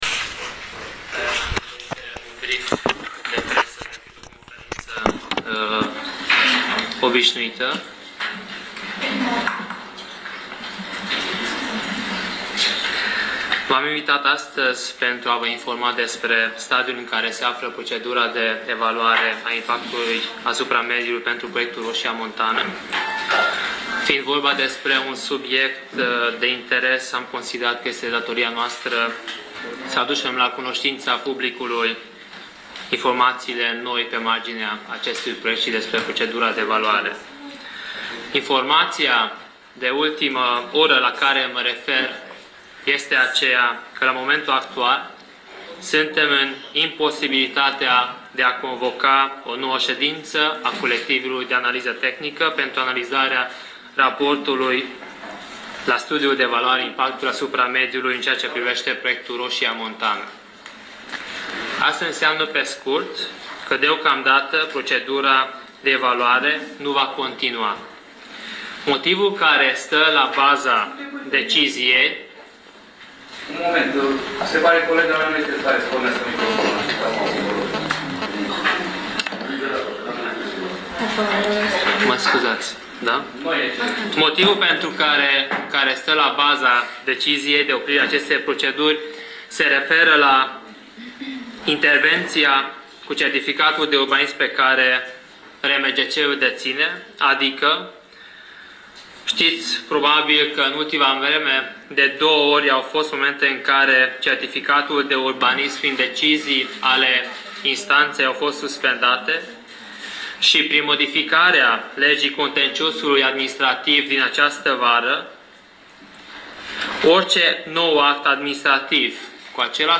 Conferință de Presa - Roșia Montană
Conferință de Presa – înregistrare audio